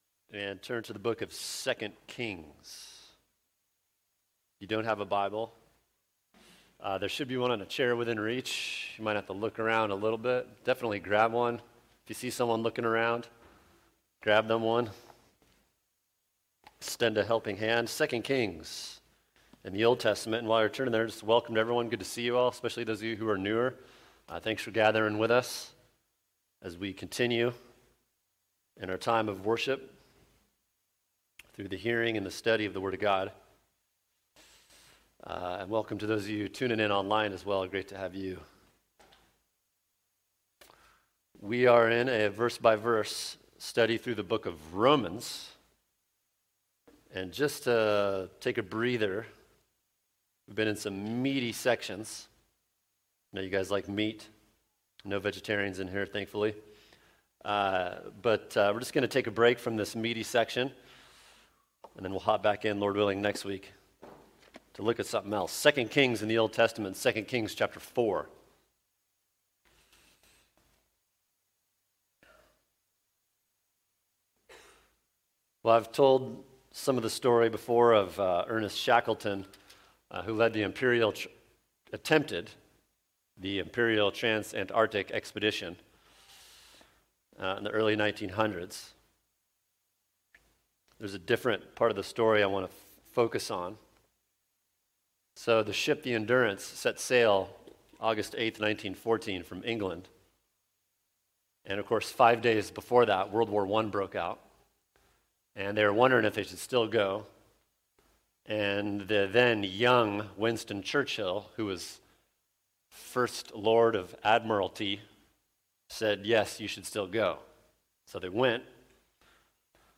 [sermon] 2 Kings 4:8-37 The Ups and Downs of God’s Ways | Cornerstone Church - Jackson Hole